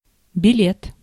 Ääntäminen
IPA: [bi.jɛ]